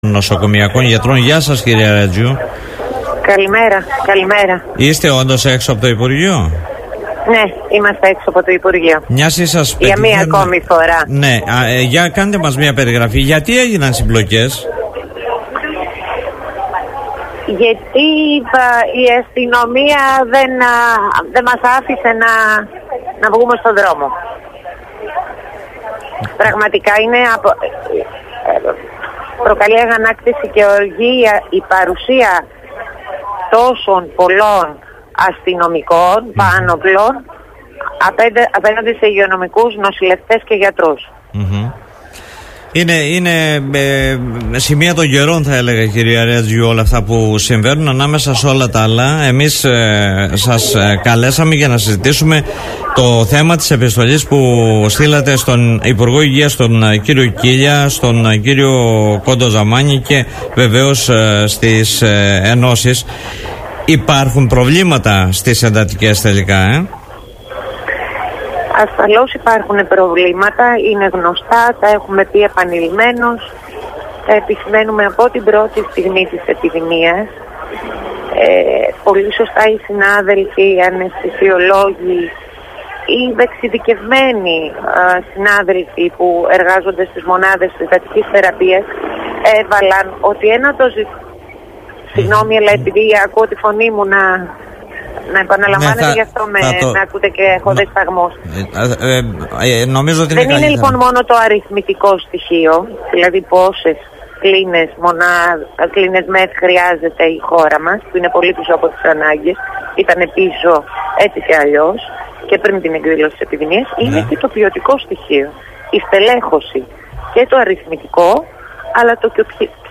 μέσα από την συχνότητα του Politica 89.8